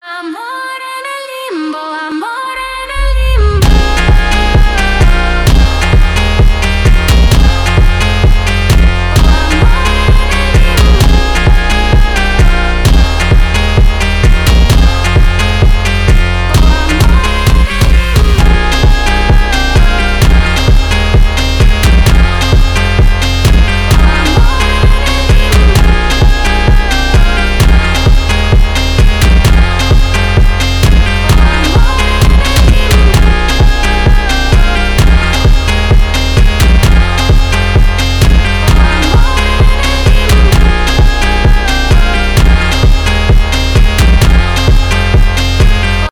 басы , громкие , фонк